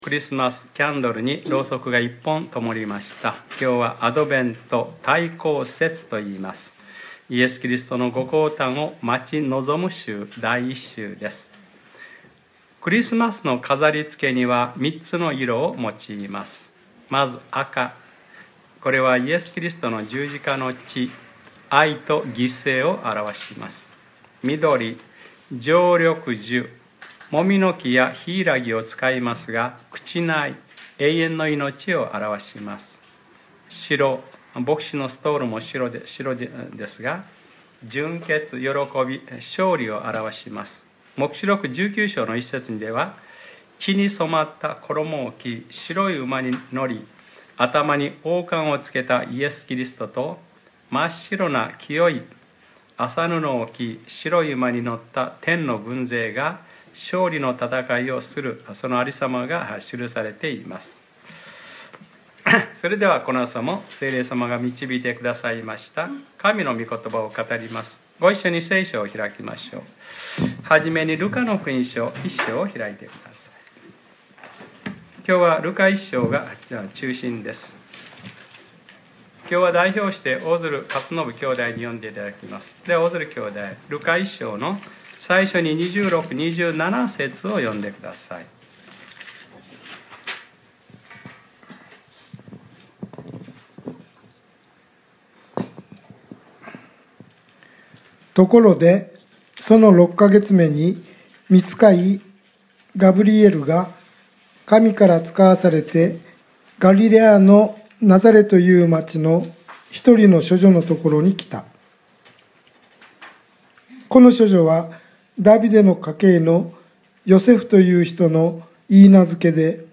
2019年12月01日（日）礼拝説教『マリヤの信仰』